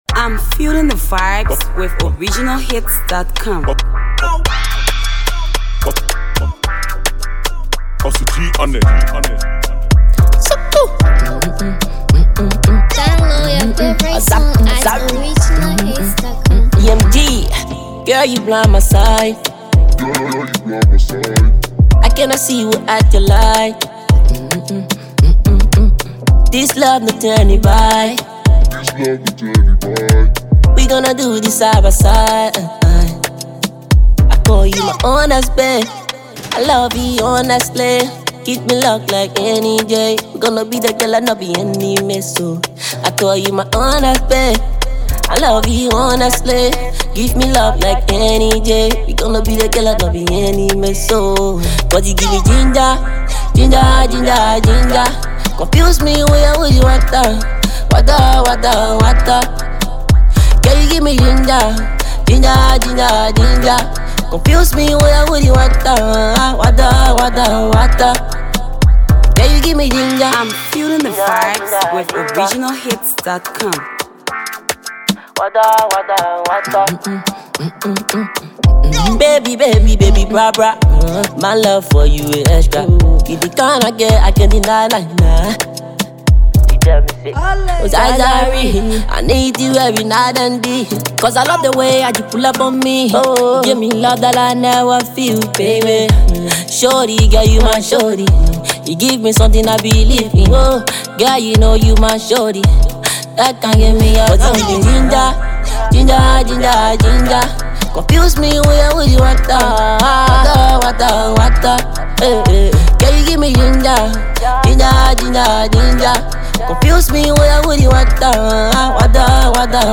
chilling tune banger